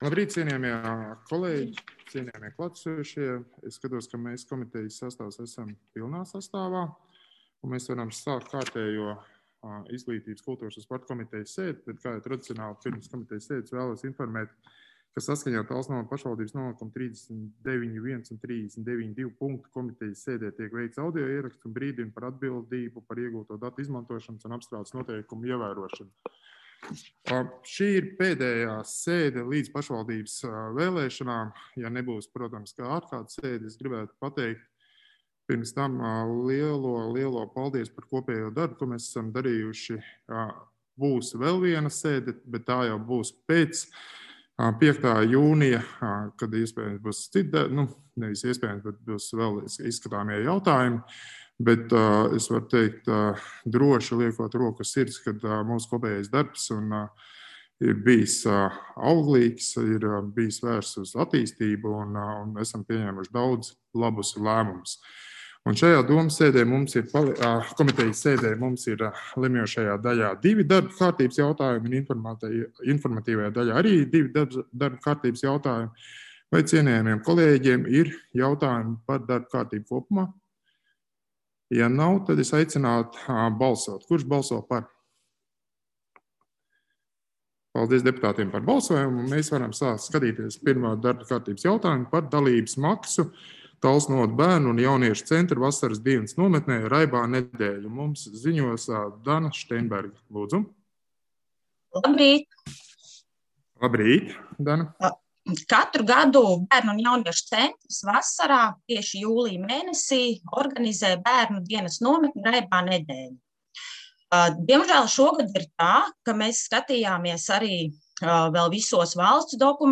5. Izglītības, kultūras un sporta komitejas sēdes protokols